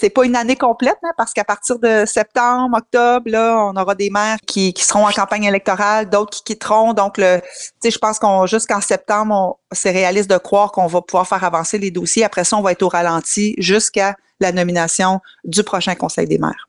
En effet, l’élection municipale du 2 novembre amènera certains maires à s’occuper de leur campagne électorale comme l’a expliqué la préfète de la MRC de Nicolet-Yamaska, Geneviève Dubois.
lundi-genevieve-dubois.wav